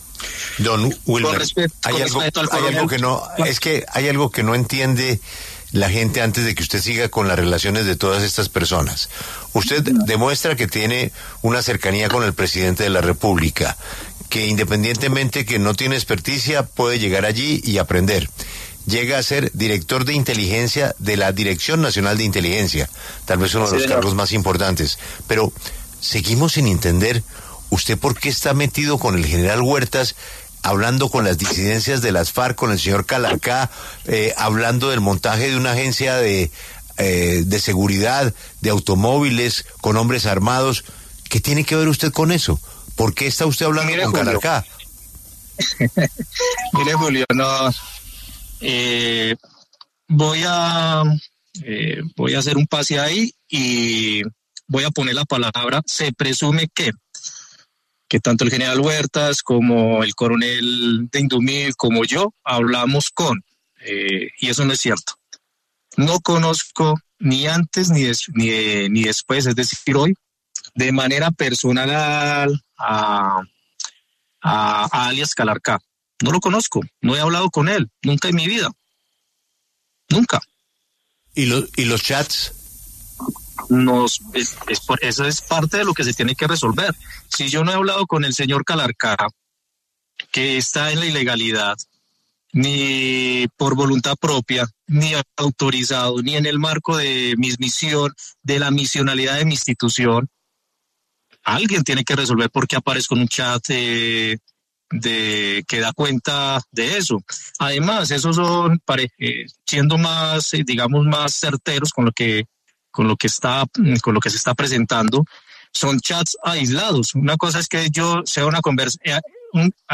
Mejía, en la entrevista, negó conocerlo personalmente.